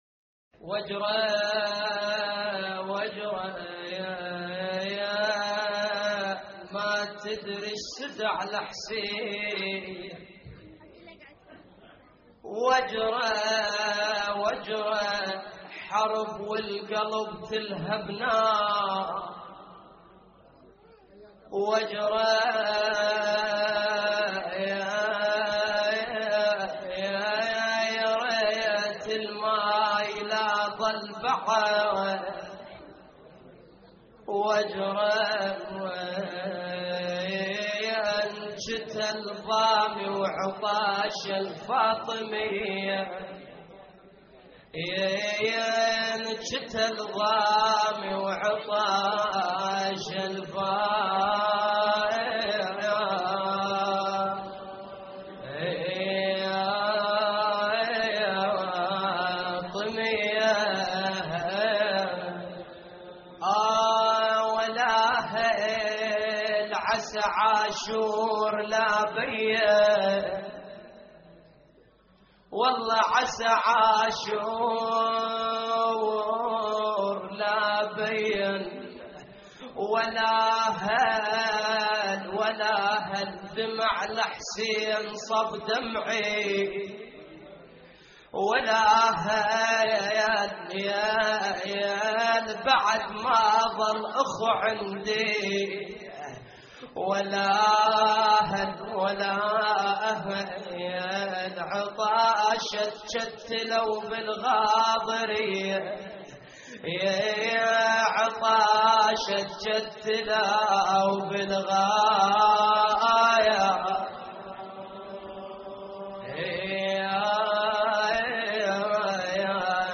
واجرى ما تدري السده على حسين (نعي
اللطميات الحسينية